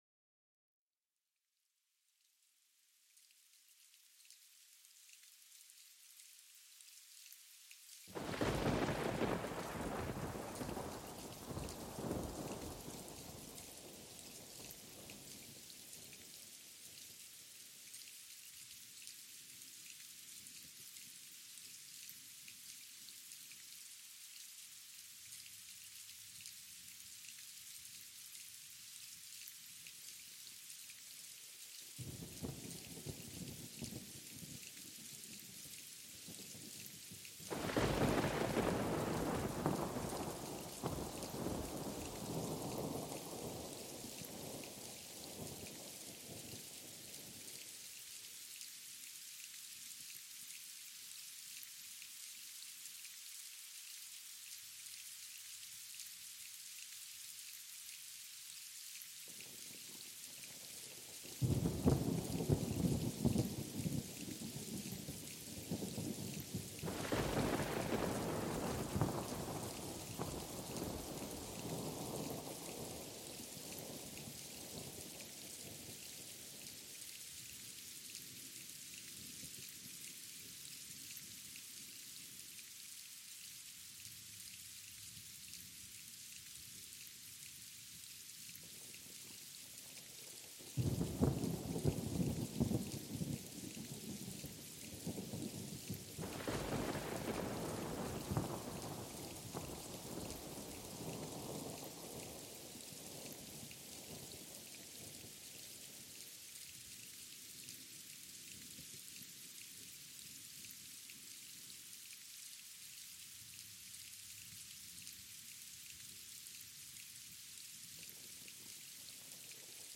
Tormenta y Lluvia Relajante para un Sueño Reparador